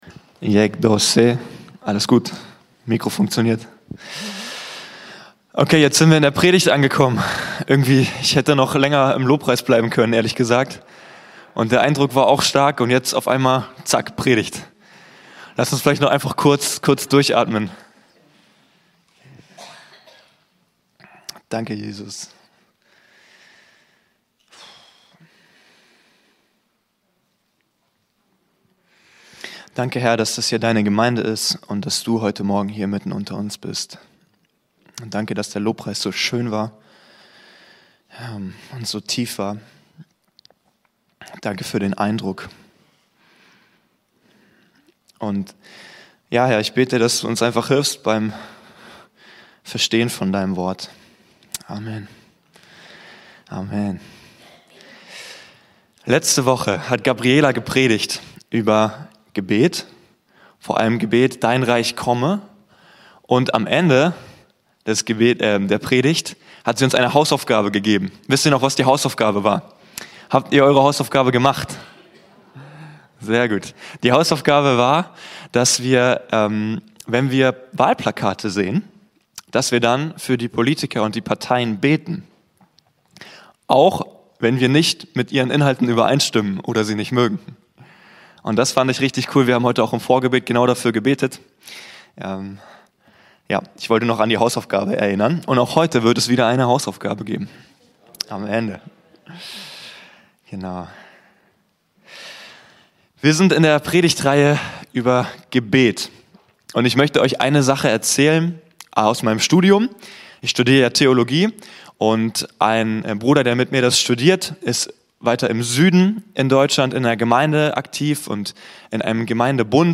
Predigtreihe: Gebet, Teil 2: Empfangendes Gebet